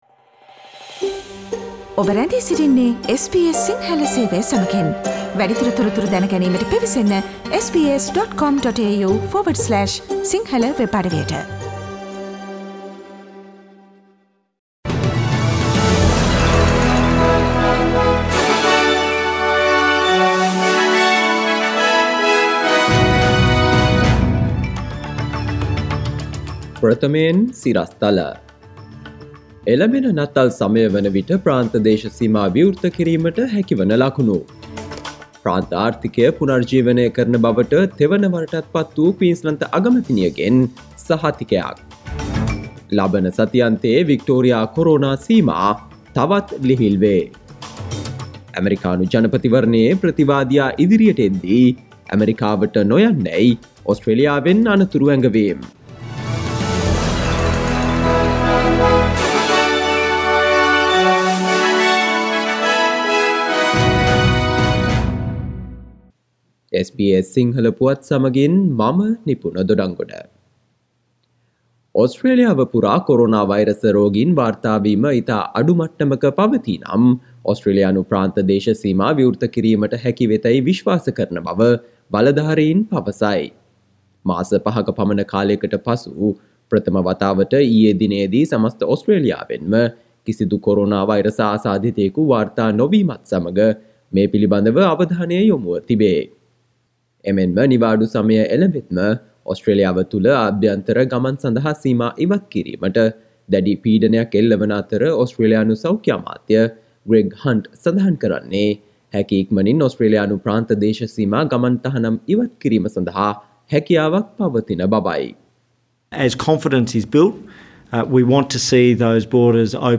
Daily News bulletin of SBS Sinhala Service: Monday 02 November 2020